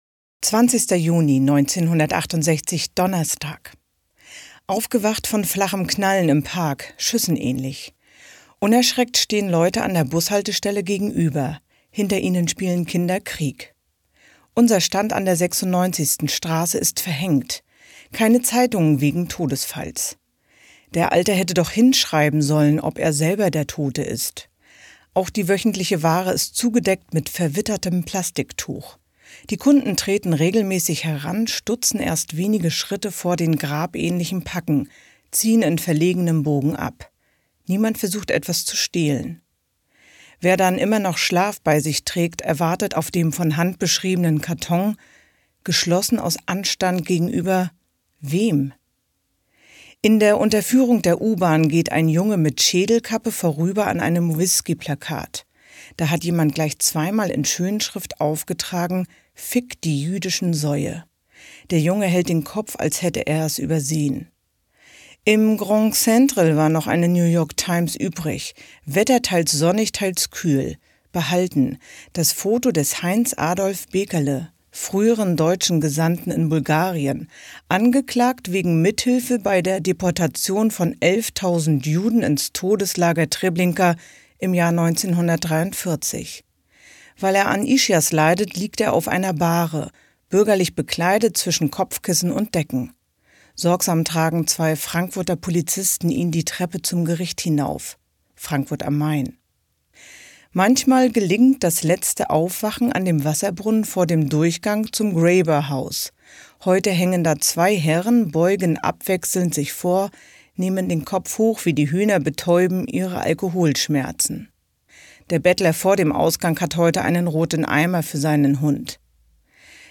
Eine Stadt liest Uwe Johnsons Jahrestage - 20. Juni 1968